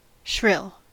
Ääntäminen
Synonyymit acerbe Ääntäminen France: IPA: [pɛʁ.sɑ̃] Haettu sana löytyi näillä lähdekielillä: ranska Käännös Ääninäyte Adjektiivit 1. sharp GenAm US 2. piercing US 3. shrill US 4. sharp-toned 5. bitter US Suku: m .